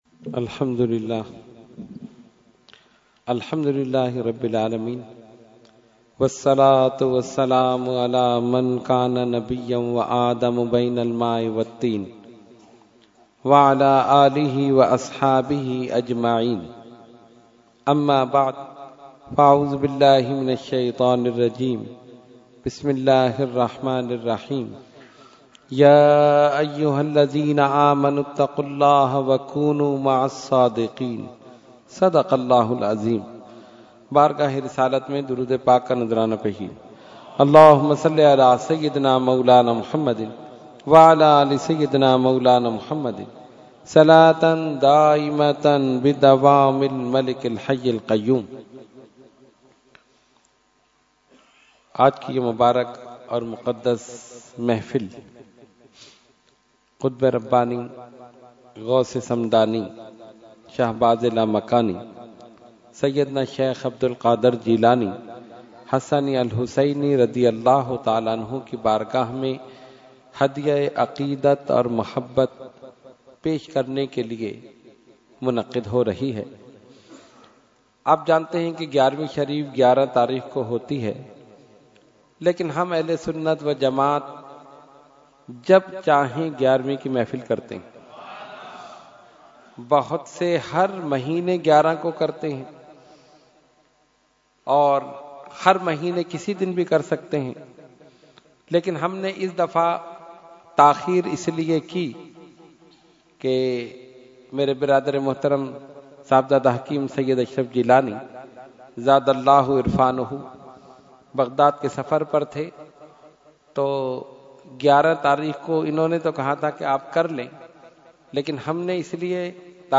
Category : Speech | Language : UrduEvent : 11veen Shareef 2018